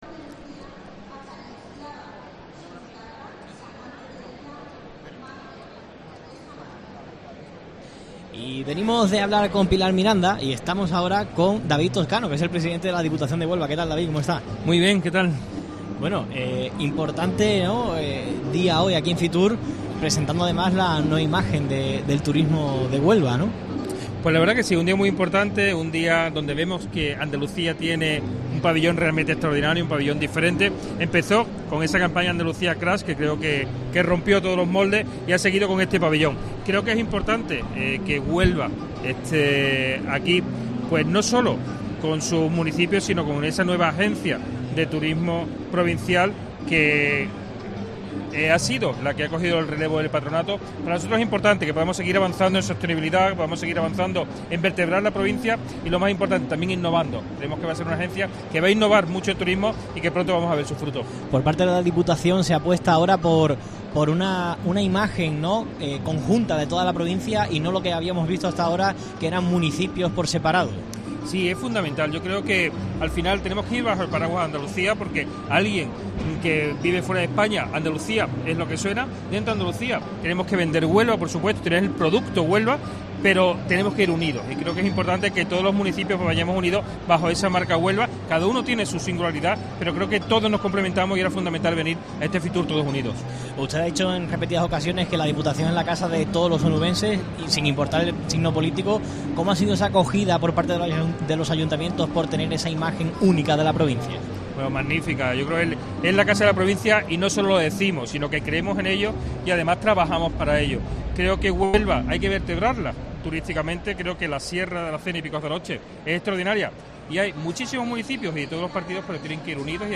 Escucha la entrevista completa a David Toscano, presidente de la Diputación de Huelva, en la primera jornada de FITUR 2024.